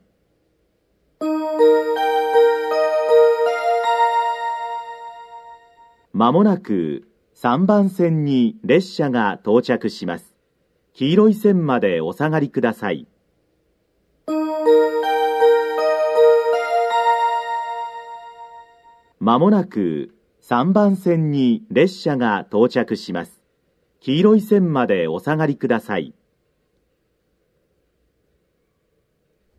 放送種類 NOA関西・武豊
スピーカー クリアホーン
接近放送
簡易放送(回送列車)
--キハ11系の回送列車で収録しました。
kuwana3_simple_a.mp3